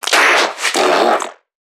NPC_Creatures_Vocalisations_Infected [67].wav